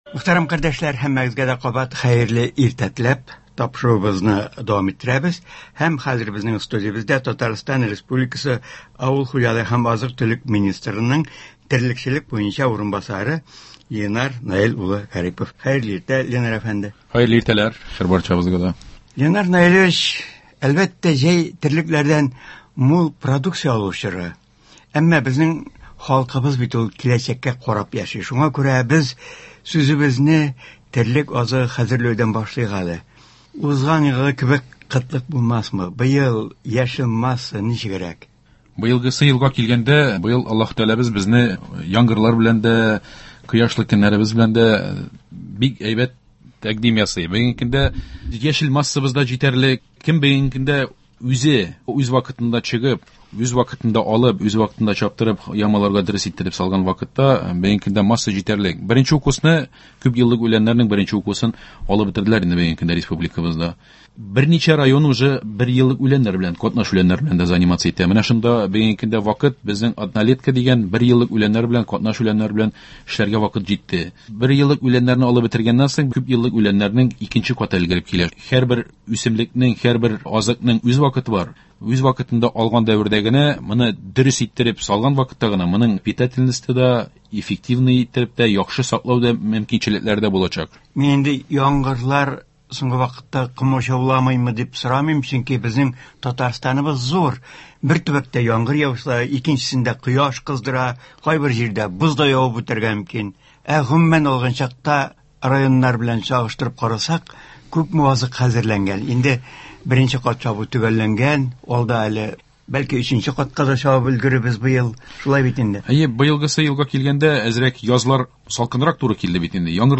Авыл хуҗалыгында киеренке чор – терлек азыгы хәзерләү бара. Быел узган елгы кебек терлек азыгына кытлык булмасмы? Бу юнәлештә әйдәп баручы районнар, шулай ук җәйге мөмкинлектән җитәрлек дәрәҗәдә файдаланмаучы хуҗалыклар, фермерлар, гаилә фермалары, инвесторлар, терлекчелек продуктлары җитештерүдә шәхси хуҗалыкларның өлеше турында турыдан-туры эфирда Татарстан республикасы авыл хуҗалыгы һәм азык-төлек министрының терлекчелек буенча урынбасары Ленар Гарипов сөйли һәм тыңлаучылар сорауларына җавап бирә.